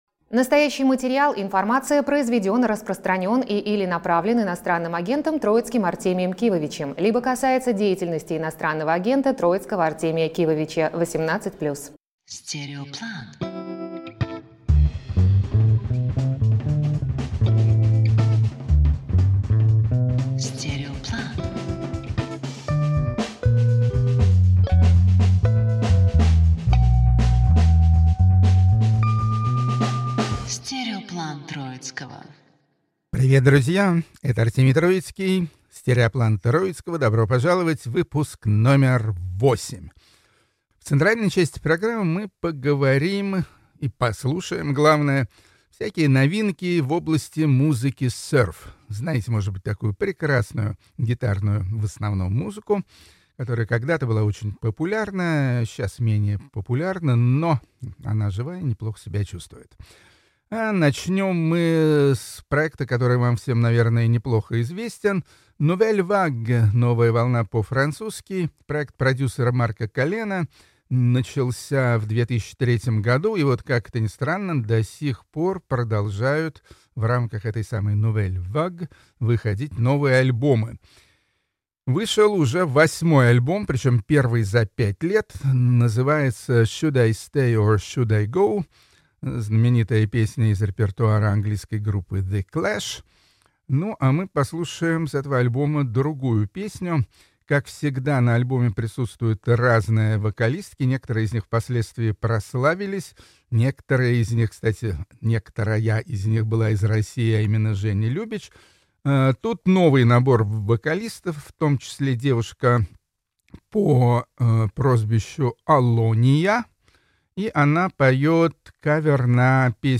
«Стереоплан Троицкого»: Музыкальный коктейль: сёрф, каверы и неожиданные сочетания